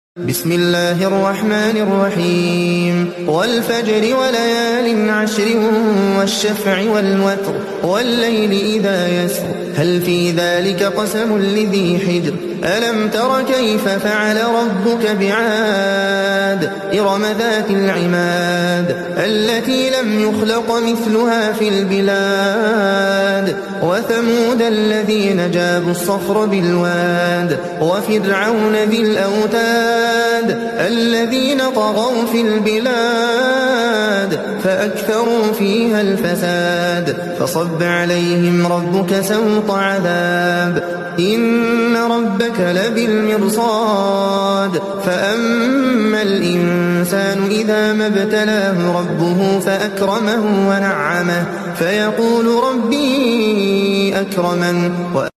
كان صوتًا جميلًا ذا قيمة، قُرئ به قول الله العظيم في القرآن الكريم.